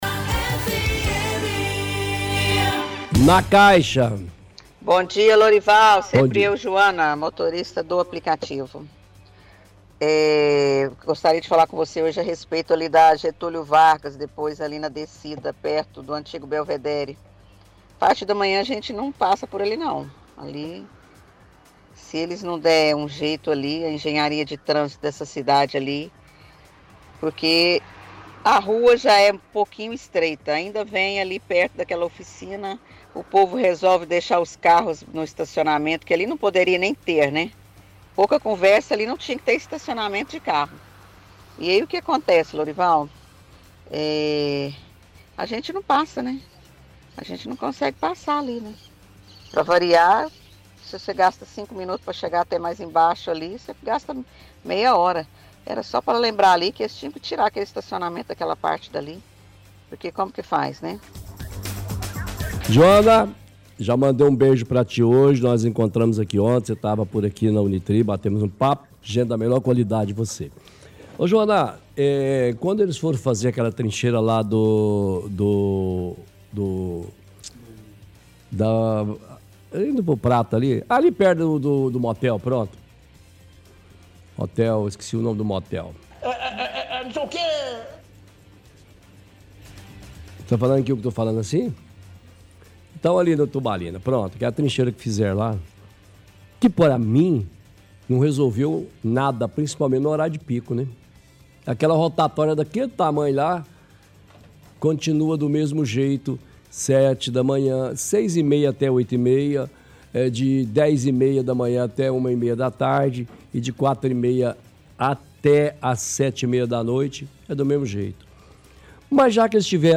– Ouvinte reclama que na Av. Getúlio Vargas é impossível passar na parte da manhã.